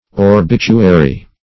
orbituary - definition of orbituary - synonyms, pronunciation, spelling from Free Dictionary Search Result for " orbituary" : The Collaborative International Dictionary of English v.0.48: Orbituary \Or*bit"u*a*ry\, a. Orbital.
orbituary.mp3